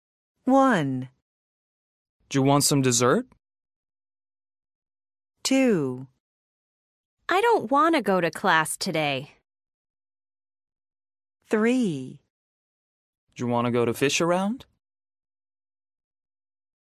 Listen to three speakers. How do they say want to?
a wanna
c/d3u/ wanna